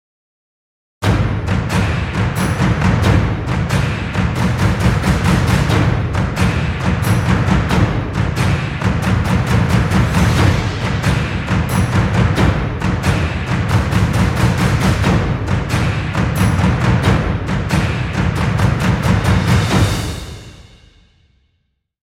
inspirational track with epic energetic.
Epic rhythm, exciting intro, or battle scenes.